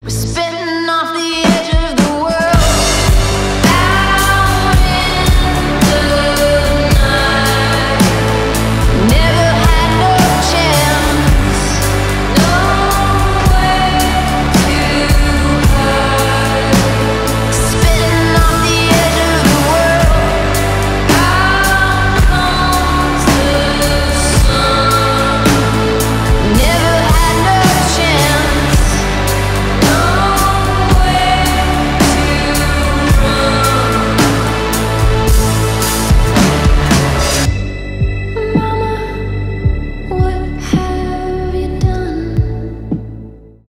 медленные
атмосферные , рок
альтернатива rock